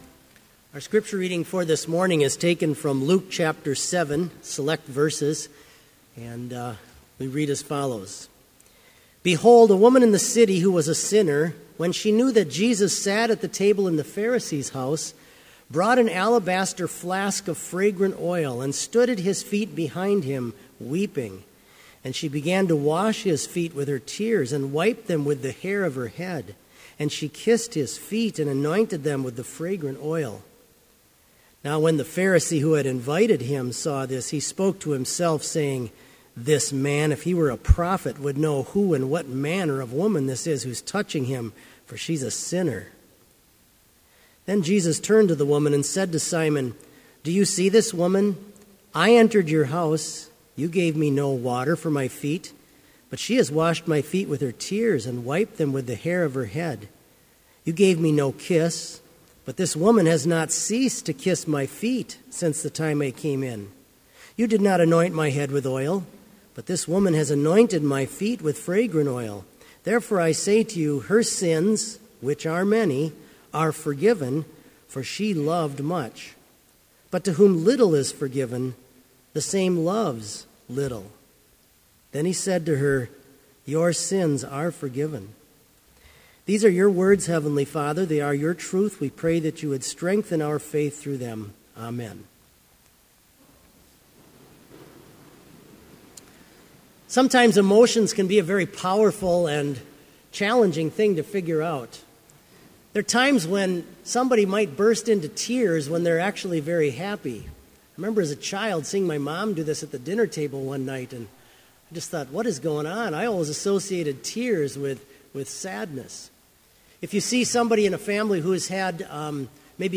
Complete service audio for Chapel - September 9, 2015